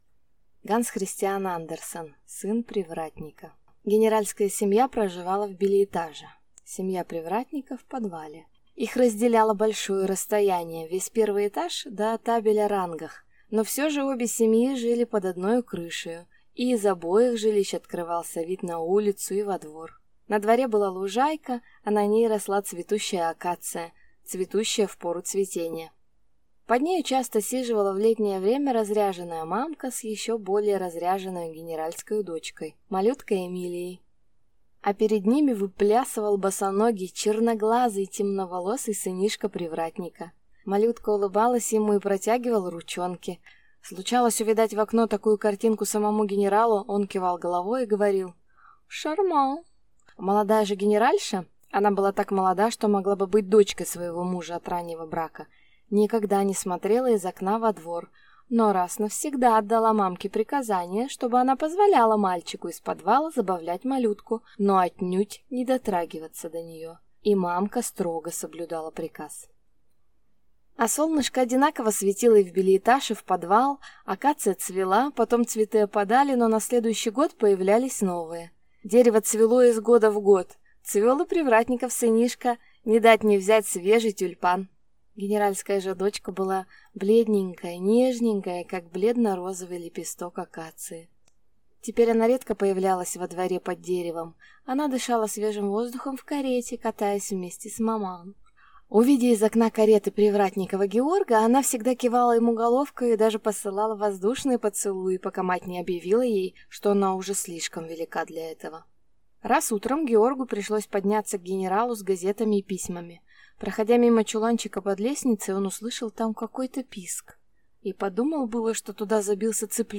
Аудиокнига Сын привратника | Библиотека аудиокниг